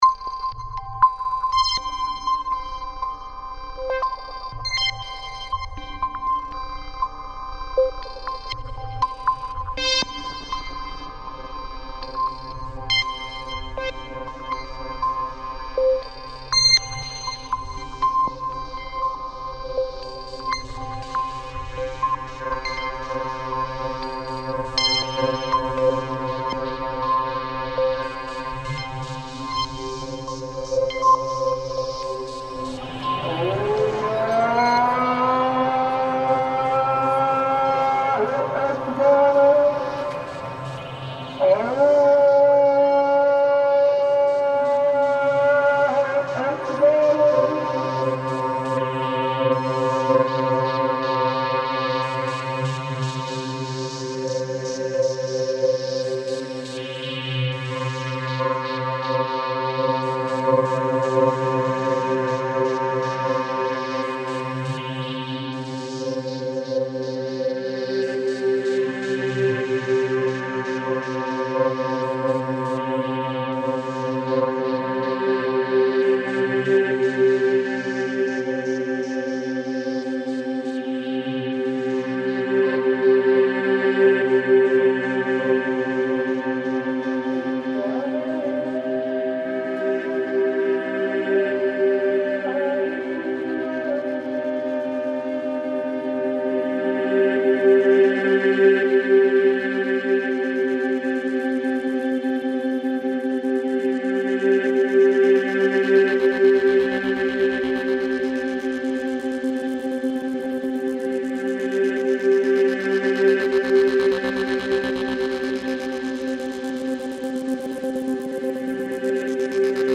Traditionally beginning with the phrase “Allahu Akbar” (“God is Greatest”), the call unfolds as a melodic and contemplative invocation.